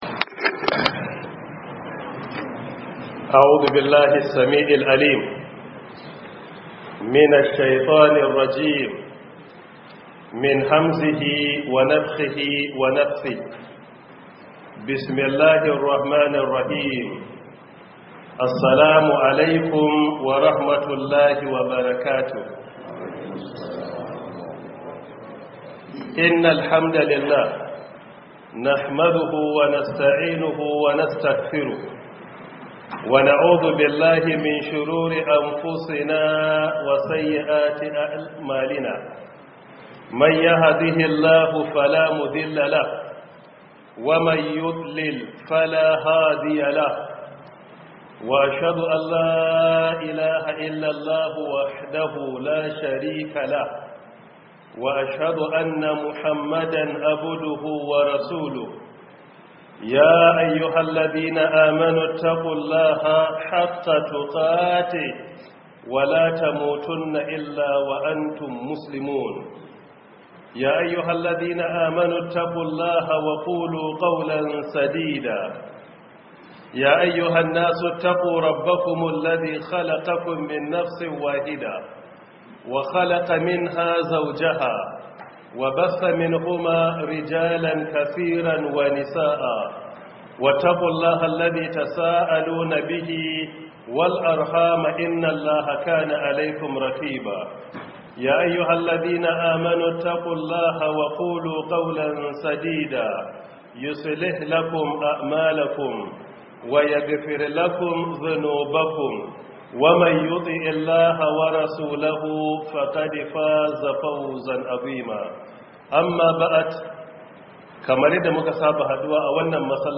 Audio lecture by Professor Isa Ali Ibrahim Pantami — 1447/2026 Ramadan Tafsir